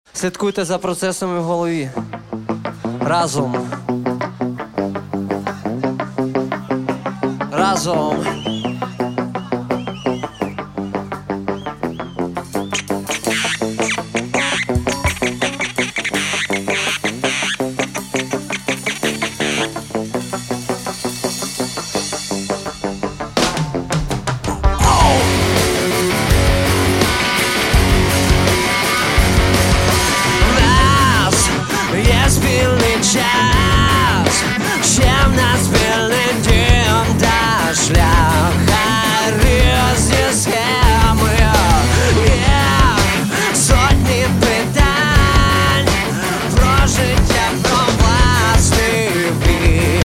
Рок (320)